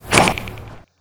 openwings.wav